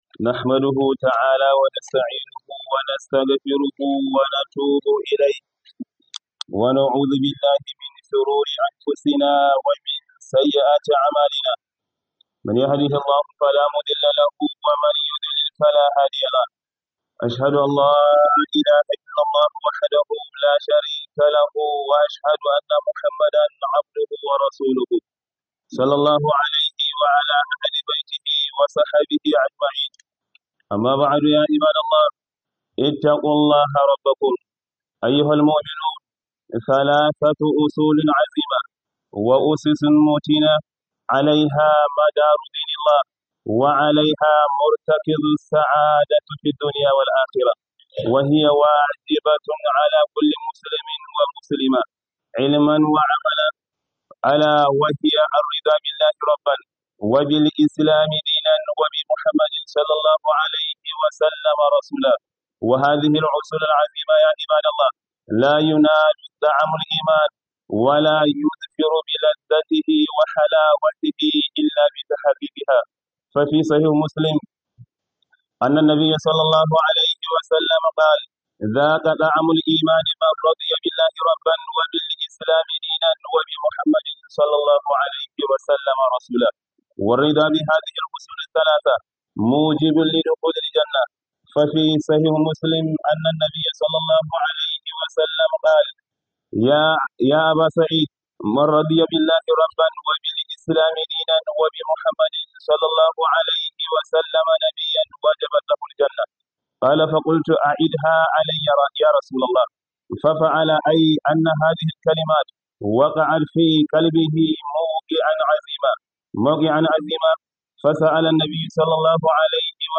Khuduba